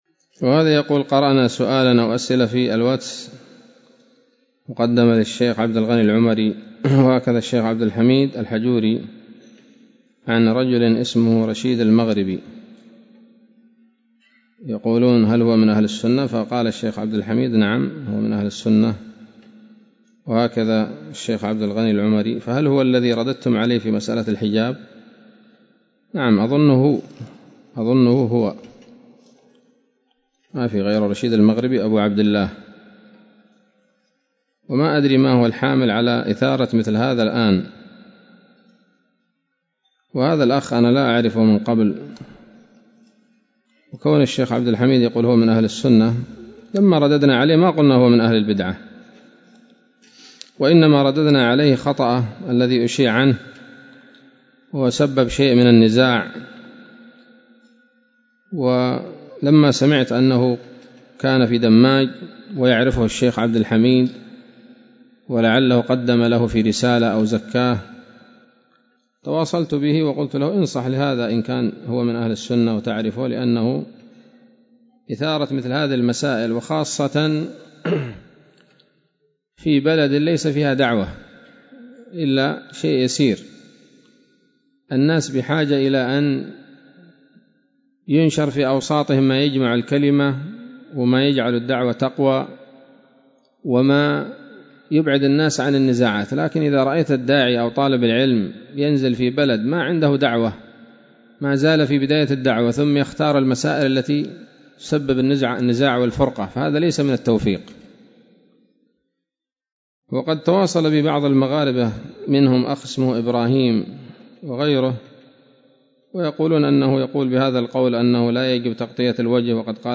إجابة عن سؤال بعنوان: (( ما حال رشيد المغربي؟ )) ظهر السبت 21 من شهر جمادى الآخرة لعام 1441 هـ، بدار الحديث السلفية بصلاح الدين.